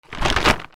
レジャーシートを広げて地面に敷く